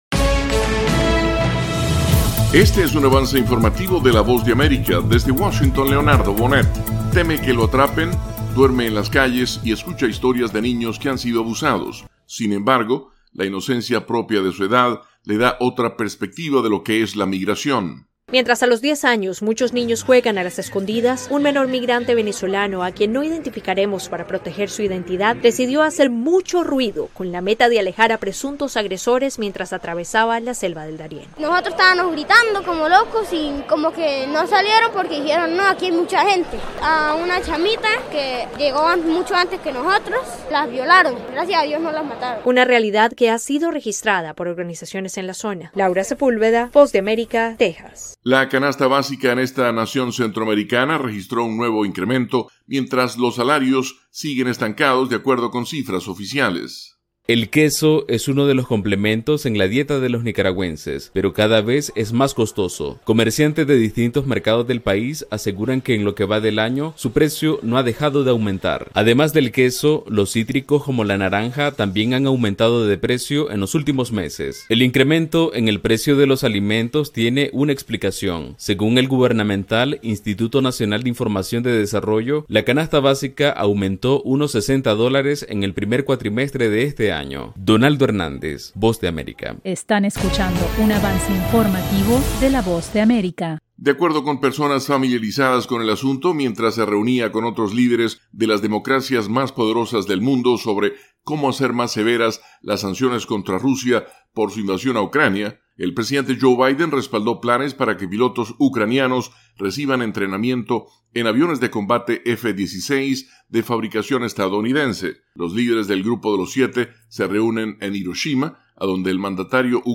Avance Informativo 3:00 PM